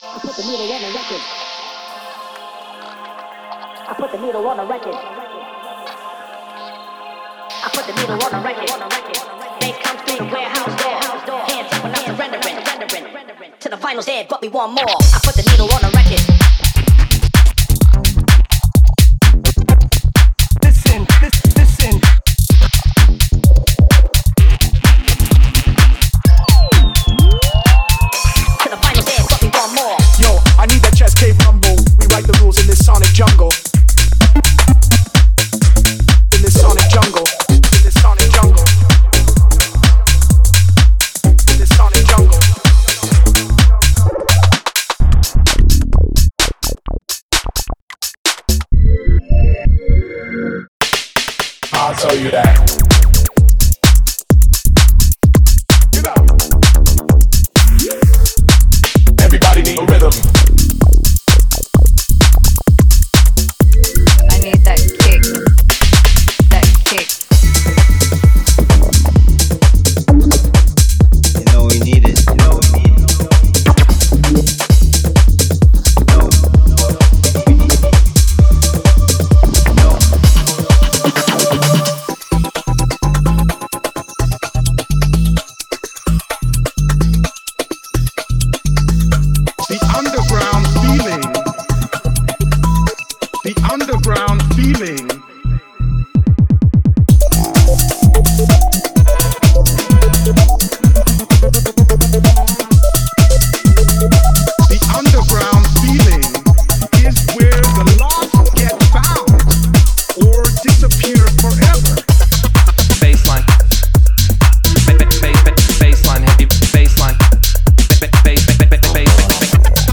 Genre:Tech House
ダンスフロアを狙い撃ちする強力な4つ打ちプロダクションに不可欠なエレクトロニック・グルーヴが満載です。
ベースループ ＞ ハイエナジーでリズミカル、そして聴き応えのあるベースループ。
デモサウンドはコチラ↓